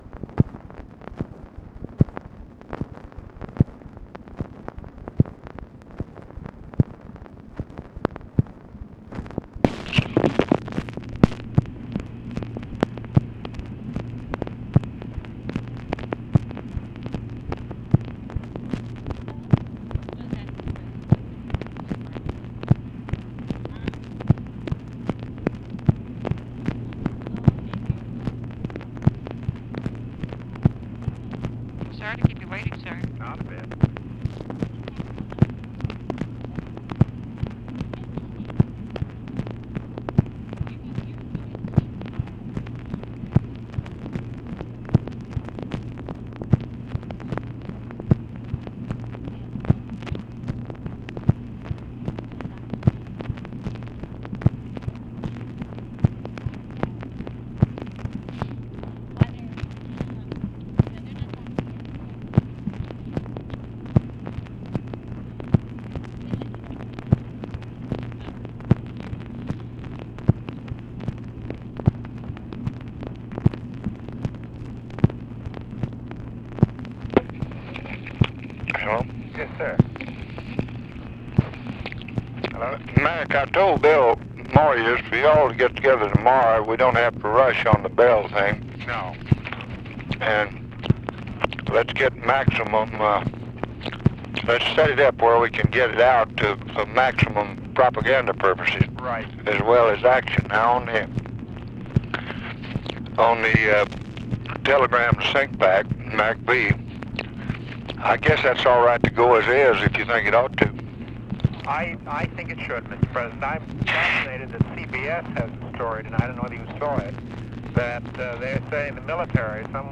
Conversation with MCGEORGE BUNDY and OFFICE CONVERSATION, December 24, 1965
Secret White House Tapes